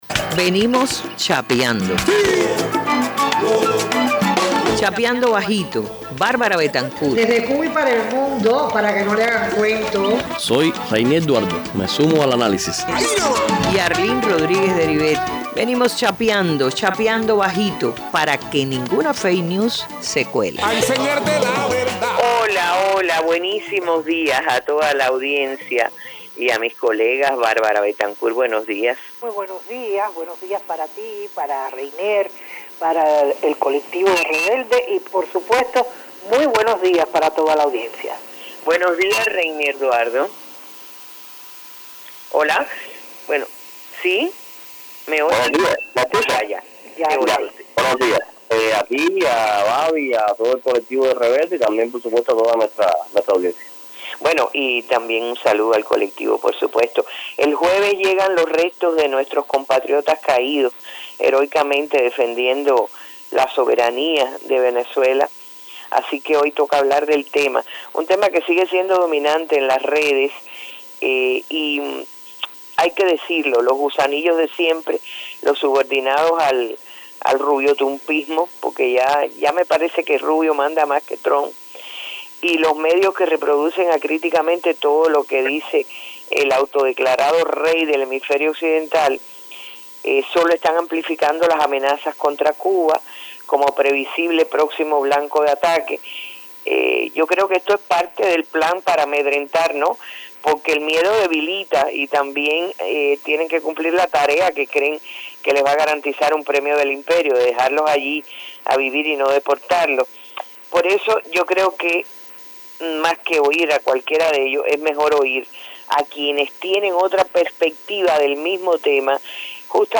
En una conversación difundida en Cubadebate con los analistas